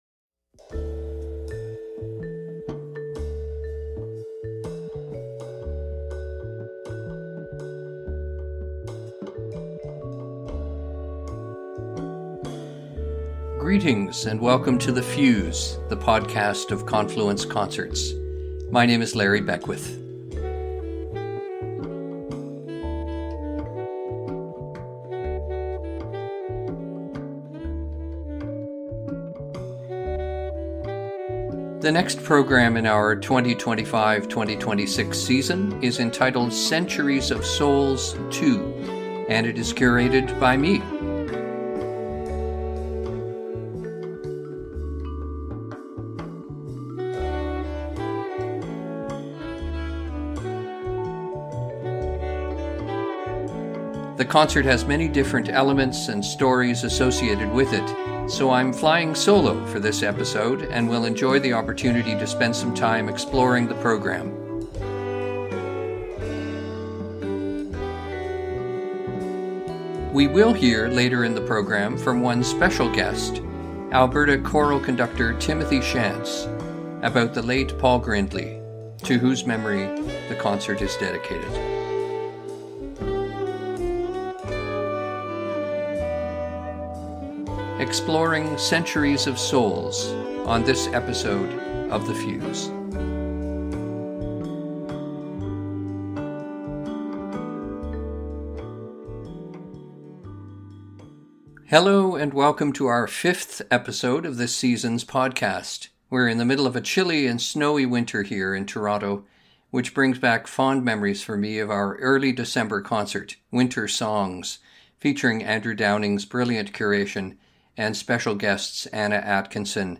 A wide-ranging podcast with interviews, features and music that celebrates the vibrant and varied musical community of Toronto and beyond.